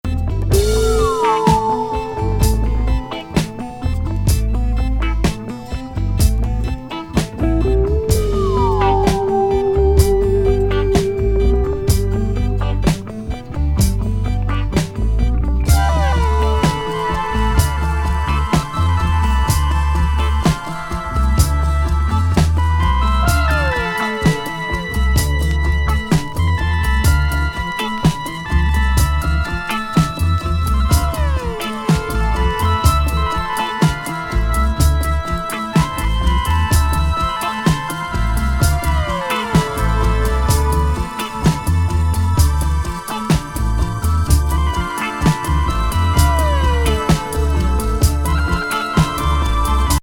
ストレンジ北欧プログレッシブ・グルーブ